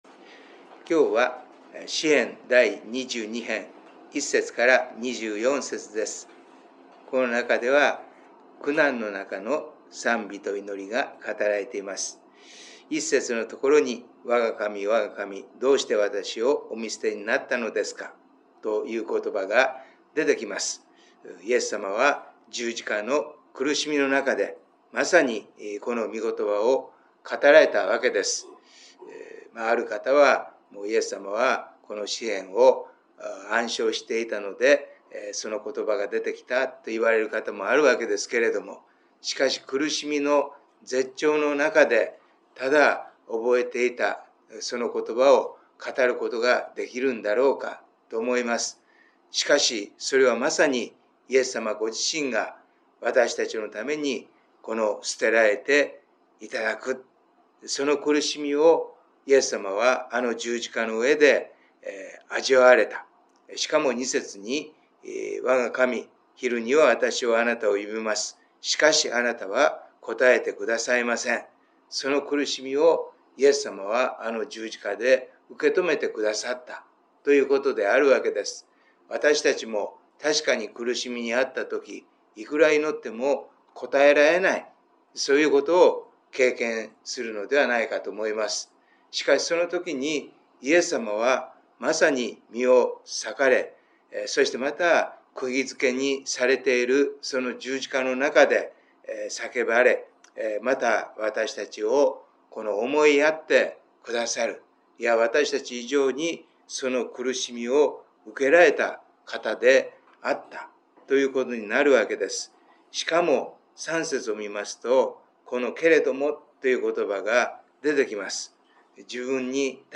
礼拝メッセージ「キリストに満たされる」│日本イエス・キリスト教団 柏 原 教 会